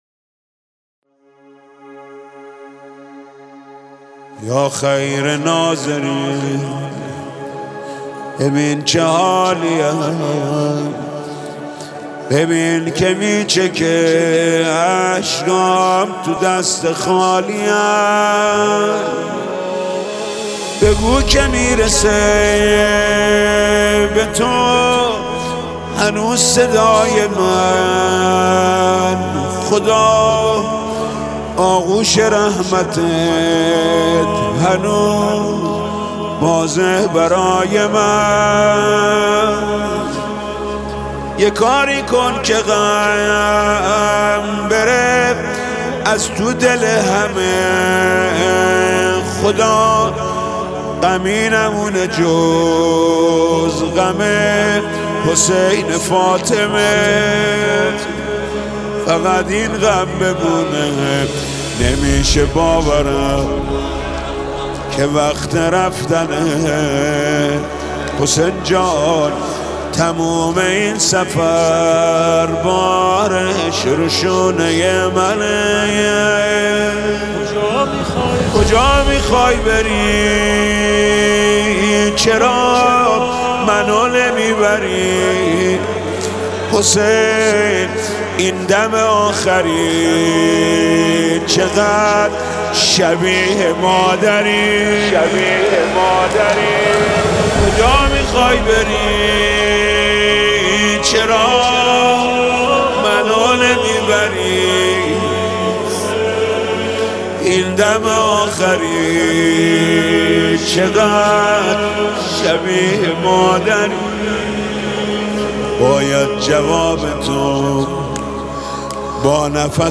روضه خوانی حاج محمود کریمی در مراسم مناجات خوانی ماه مبارک رمضان در حرم مطهر امام رضا علیه السلام را ببینید.